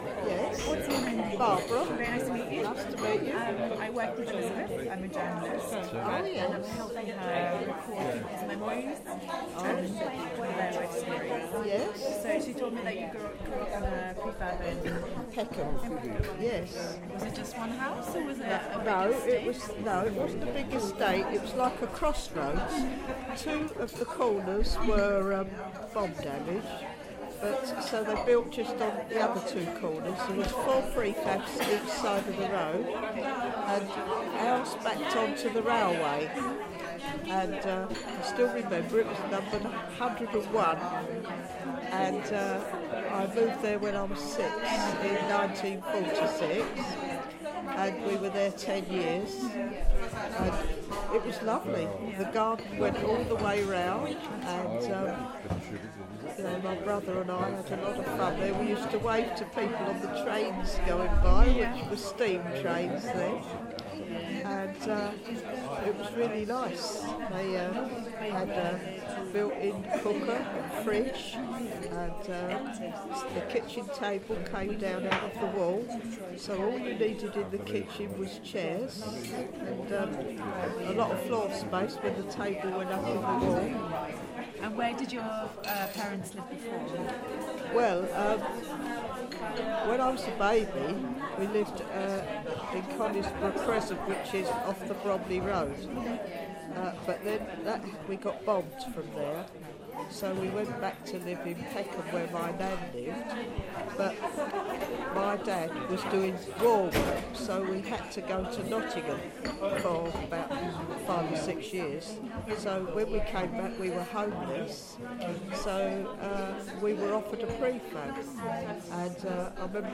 Moving Prefab Museum Event - St Mark's Church Hall, Excalibur Estate
St Marks Hall, Excalibur Estate, May 2016